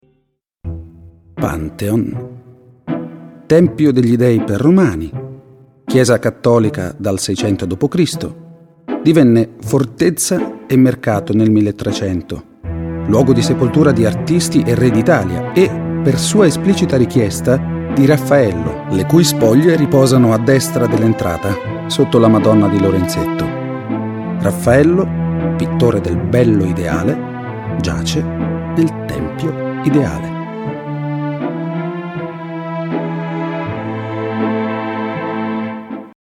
Sprecher italienisch.
Sprechprobe: Werbung (Muttersprache):